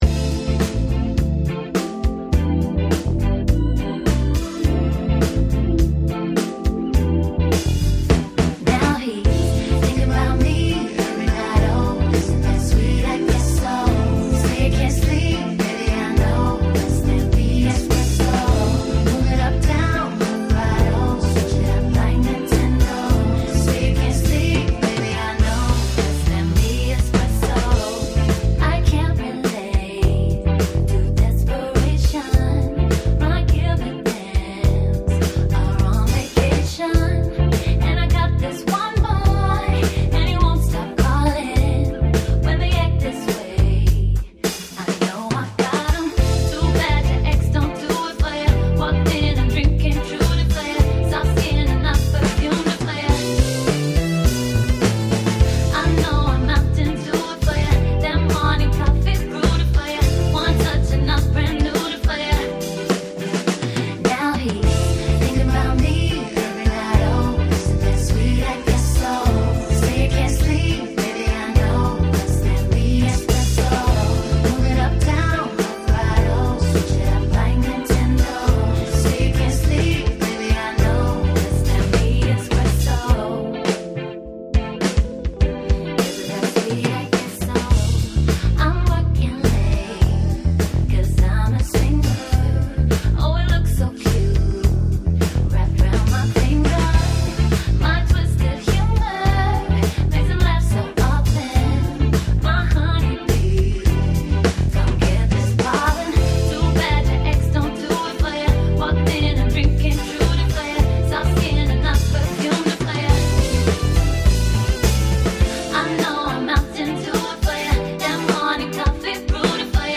Female Fronted Function Band for Hire